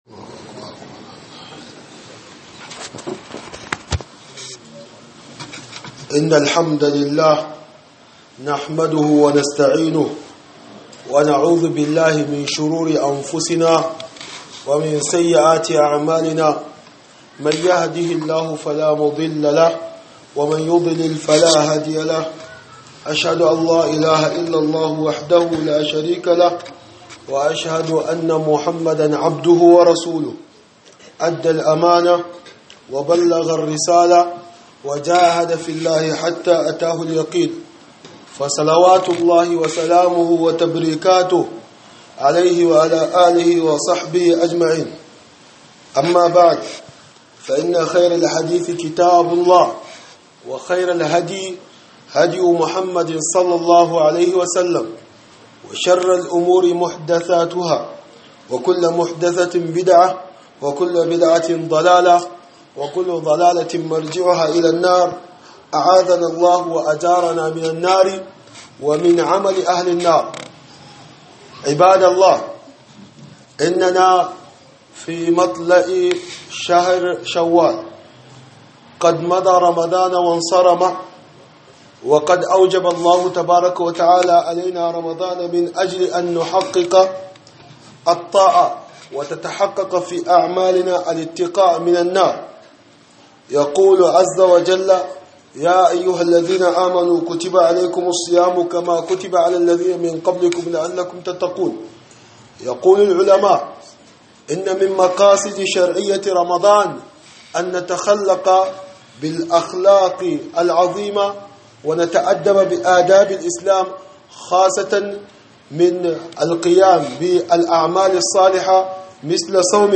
خطبة بعنوان صيام التطوع